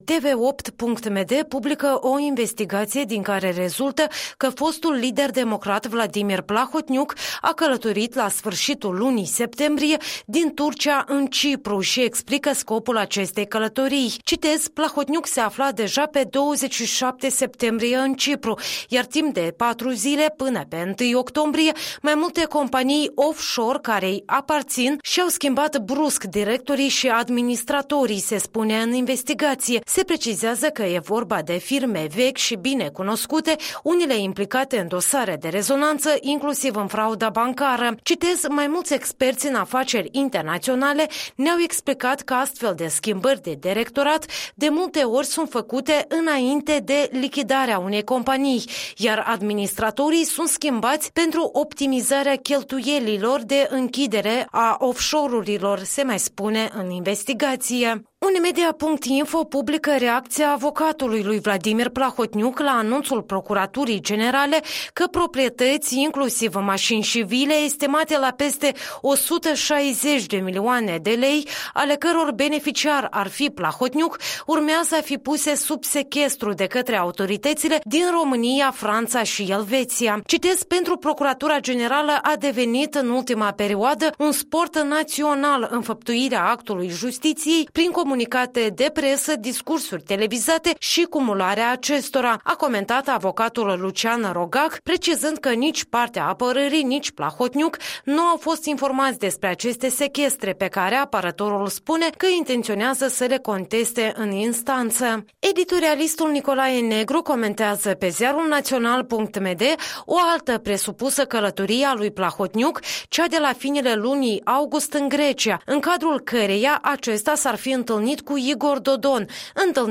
Revista matinală a presei la radio Europa Liberă.